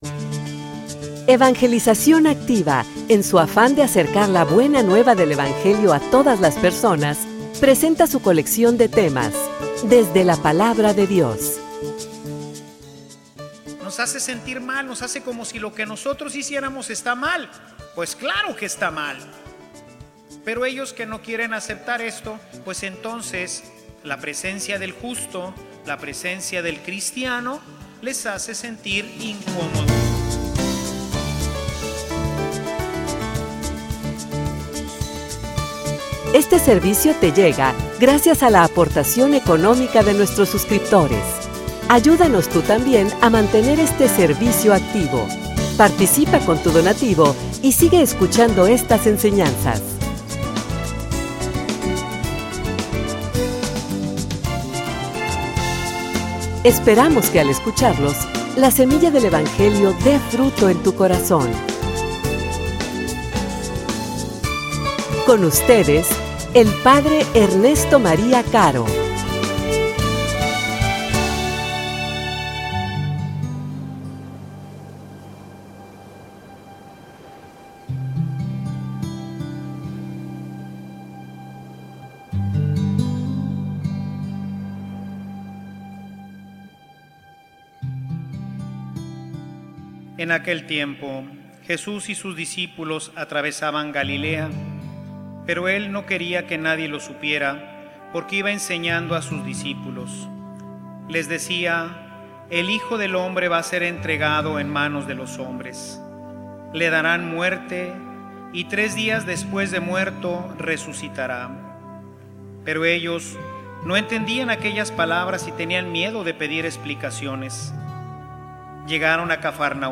homilia_Pongamoslo_a_prueba.mp3